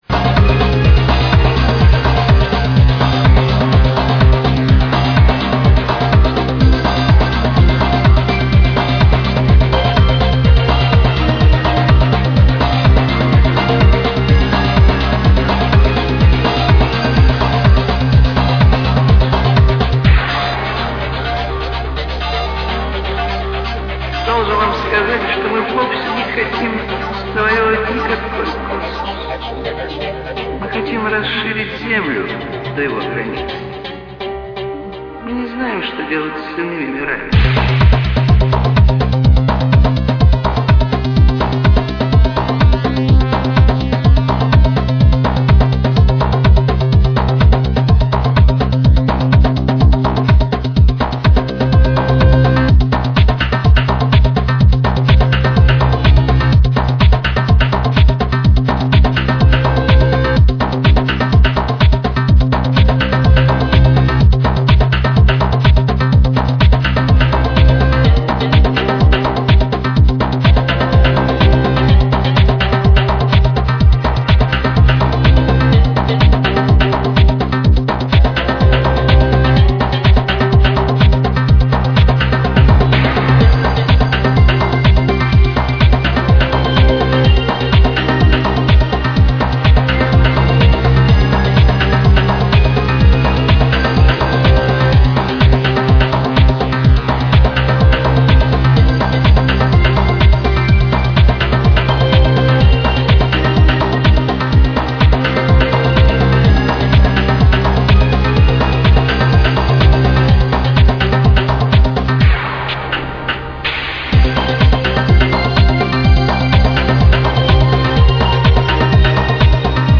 massive influence of goa-trance music and dark atmospheres